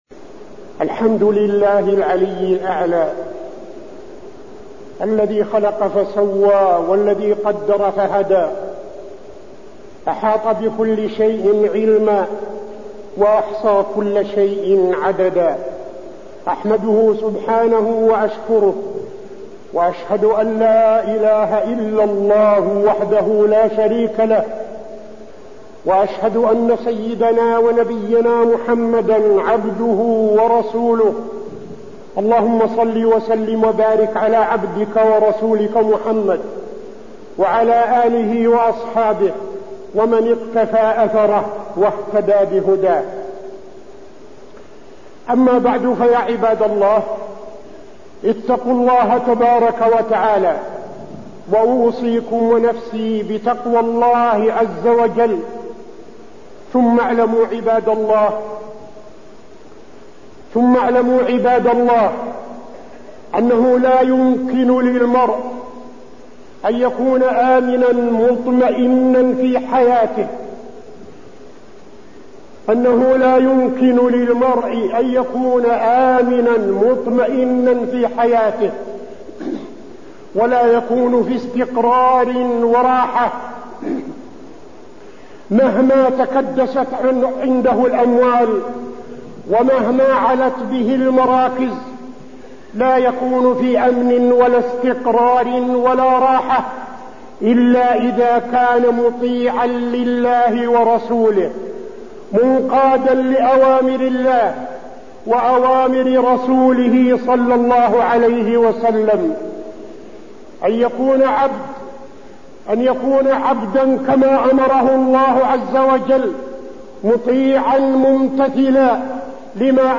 تاريخ النشر ١٣ شعبان ١٤٠٥ هـ المكان: المسجد النبوي الشيخ: فضيلة الشيخ عبدالعزيز بن صالح فضيلة الشيخ عبدالعزيز بن صالح كيف تكون آمناً مطمئناً